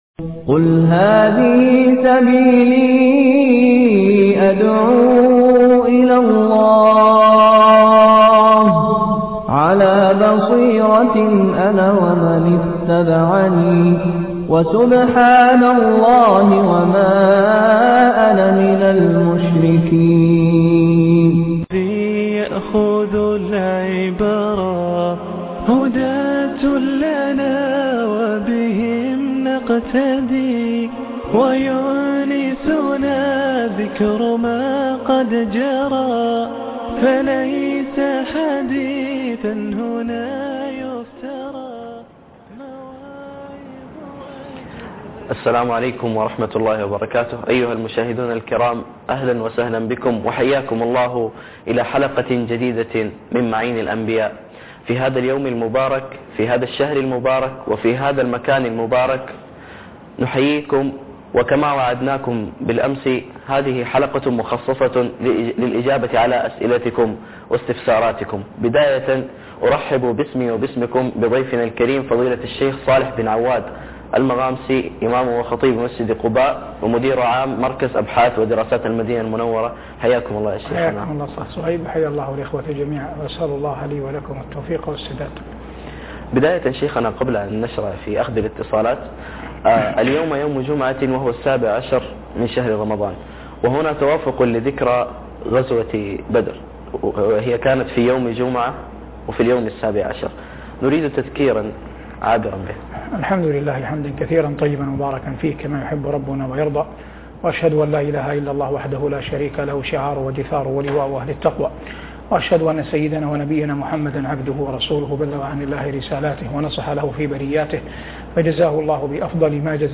حلقة مفتوحة للإجابة علي أسئلة المتصليين (27/8/2010) من معين الأنبياء - الشيخ صالح بن عواد المغامسى